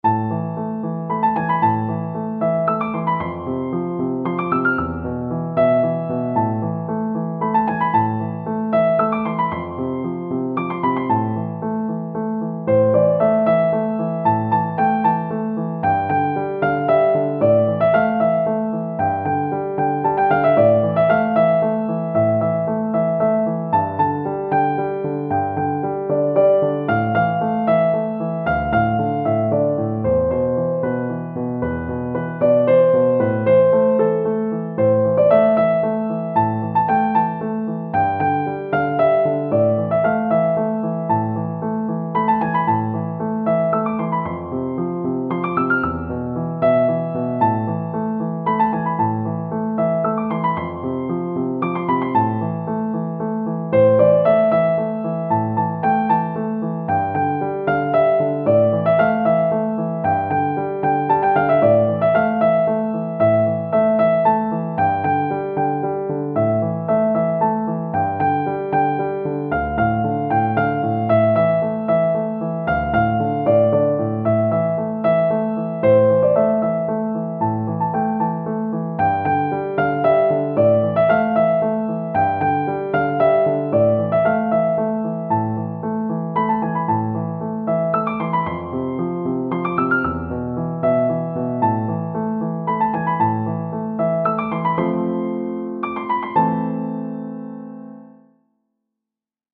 ساز : پیانو